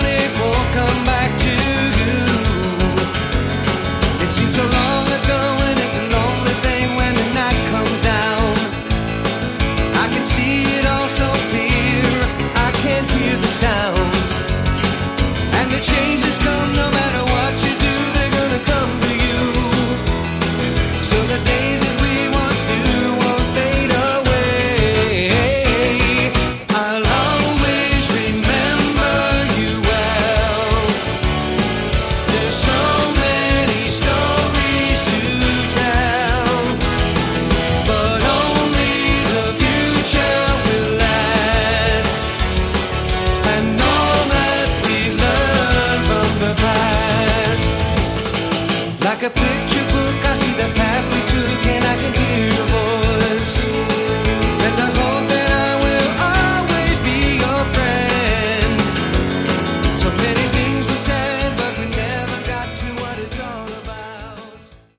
Category: AOR
guitar, bass, keyboards
drums
bonus studio track